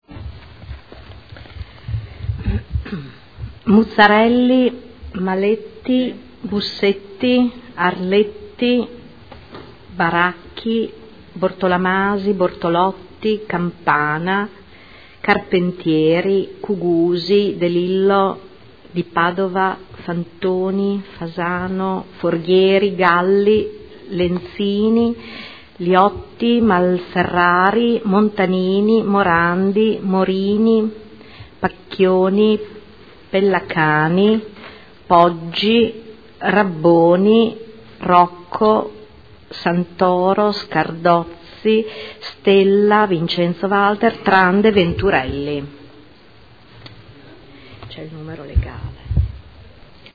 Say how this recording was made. Seduta del 16/04/2015. Appello